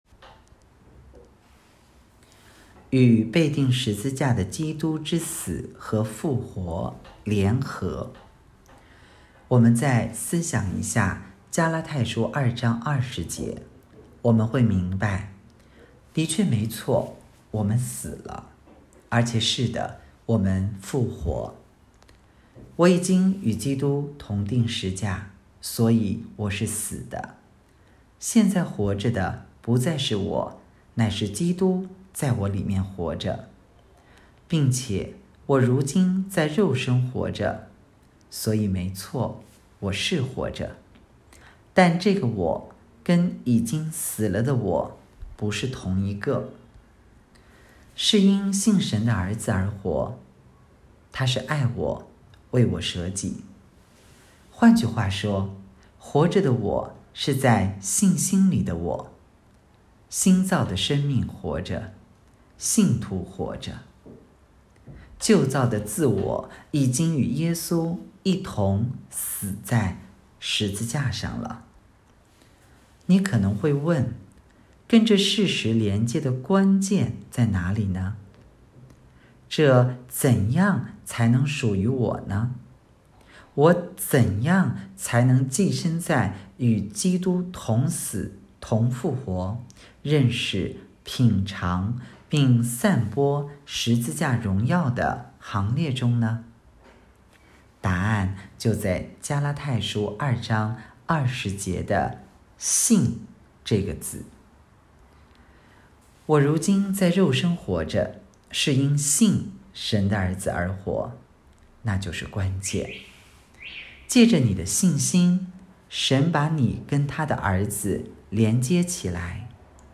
2023年10月12日 “伴你读书”，正在为您朗读：《活出热情》 音频 https